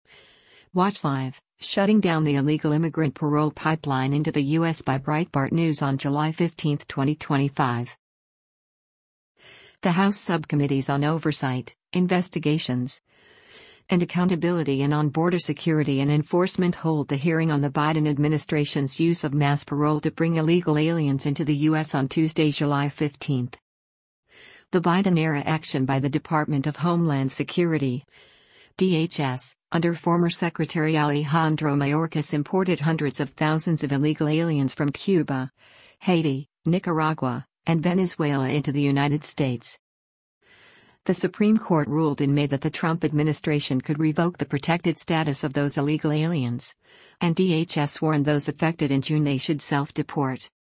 The House Subcommittees on Oversight, Investigations, and Accountability and on Border Security and Enforcement hold a hearing on the Biden administration’s use of mass parole to bring illegal aliens into the U.S. on Tuesday, July 15.